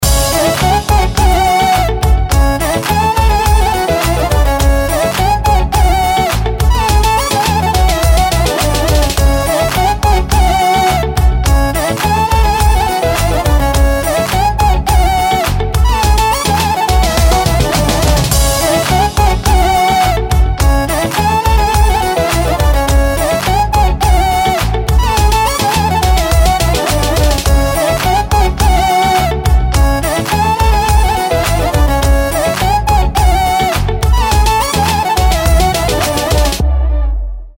• Качество: 320, Stereo
восточные
румынские